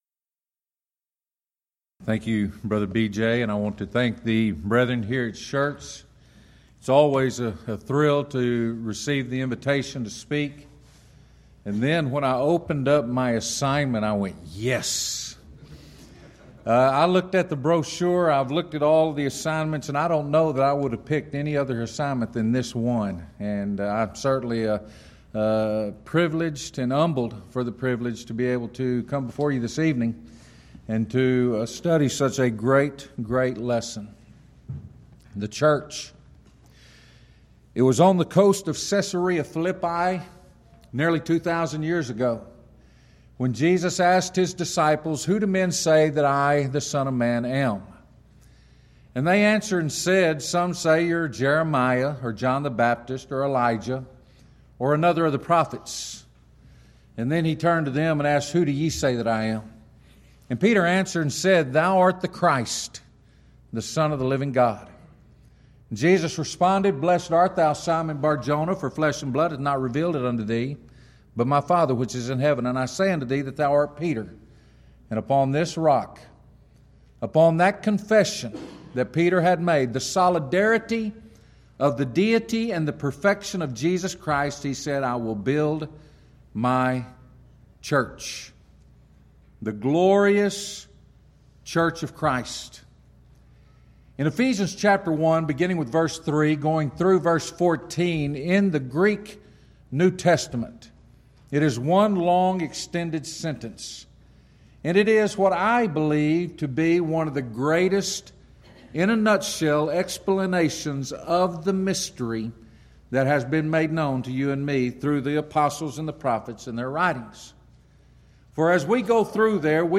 Event: 11th Annual Schertz Lectures
lecture